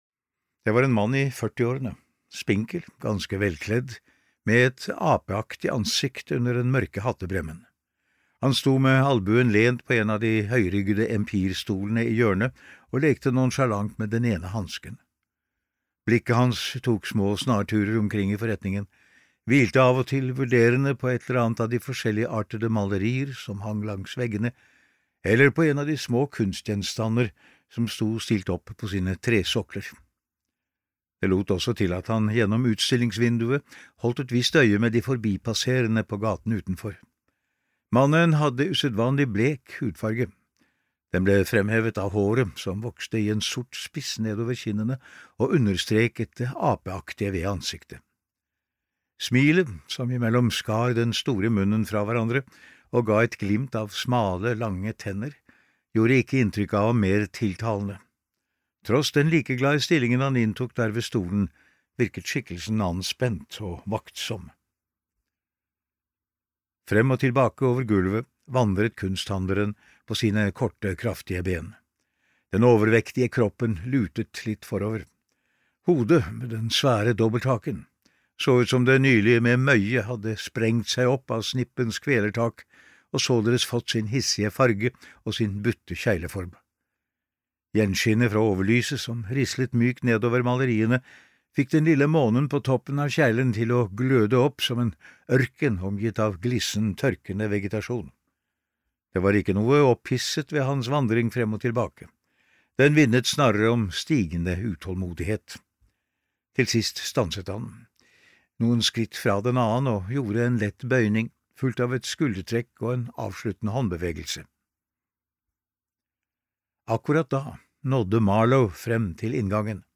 Døden går trappen ved midnatt (lydbok) av Edith Ranum